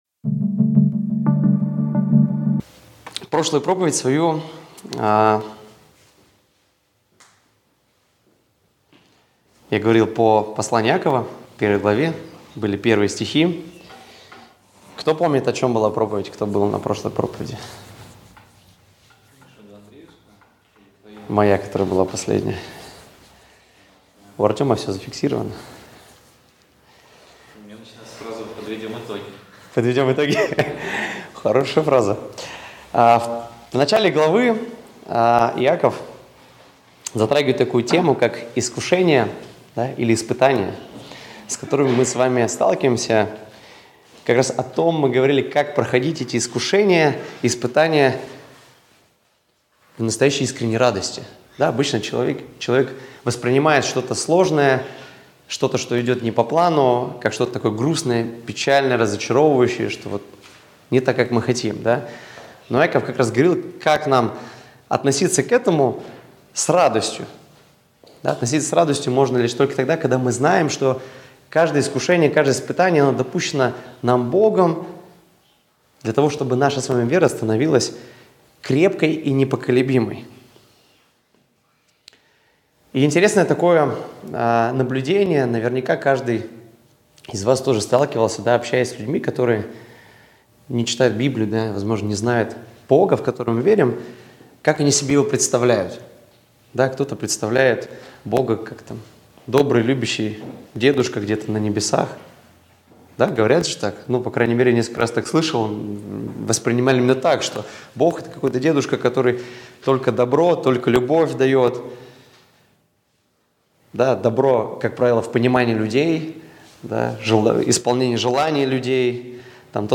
Воскресная проповедь - 2025-01-12 - Сайт церкви Преображение